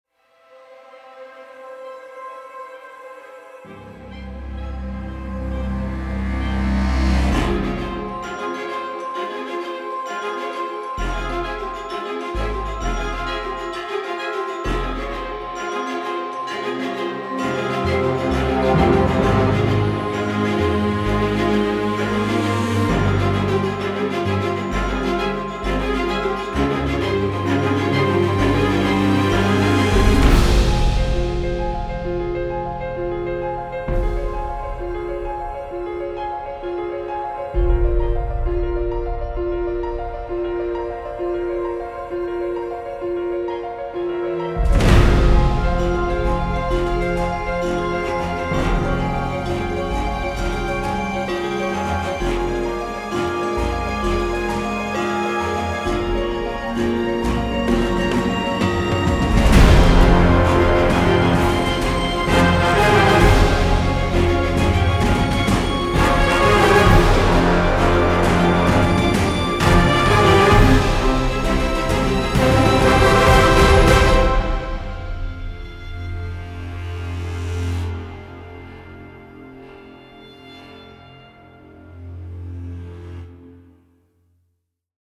ESSENTIAL MODERN WOODWIND